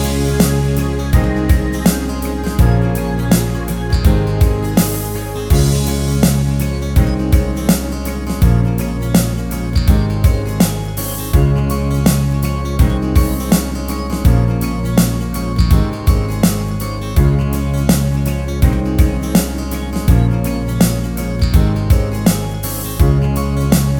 Minus Main Guitar Pop (2010s) 3:22 Buy £1.50